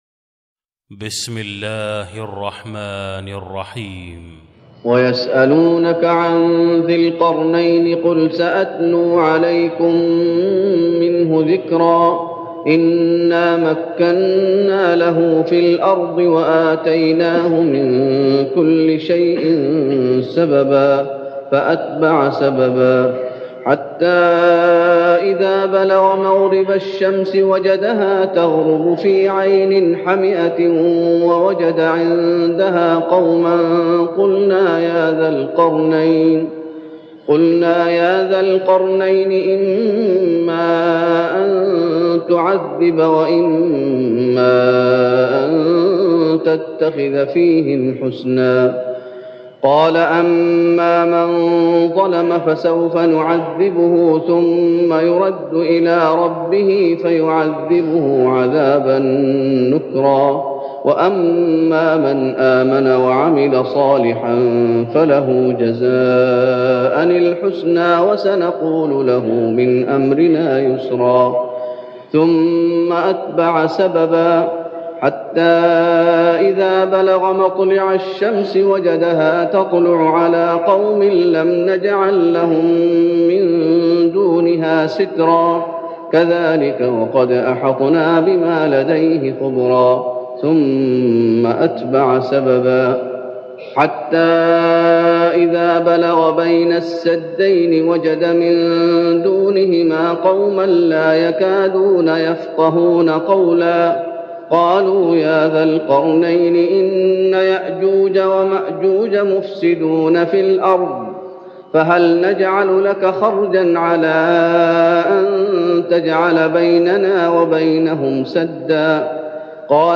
تهجد رمضان 1413هـ من سورة الكهف (83-110) Tahajjud Ramadan 1413H from Surah Al-Kahf > تراويح الشيخ محمد أيوب بالنبوي 1413 🕌 > التراويح - تلاوات الحرمين